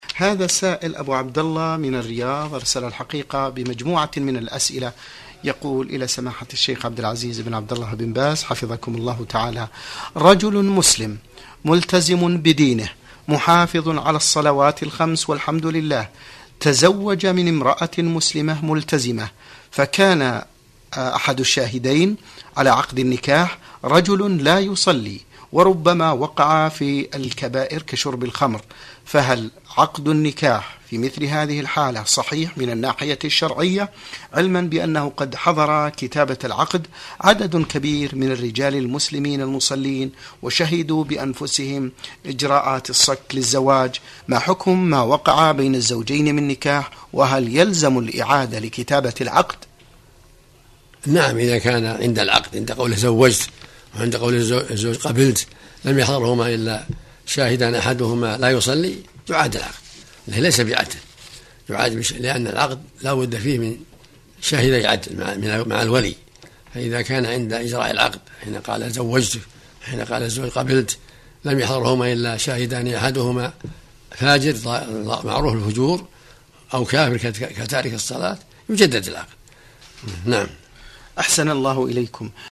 Audioaufnahme des Schaykhes aus نور على الدرب .